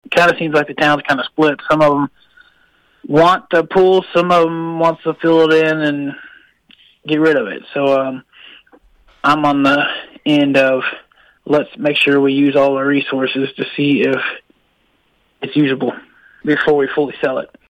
Mayor Daniel Yount says opinions are split.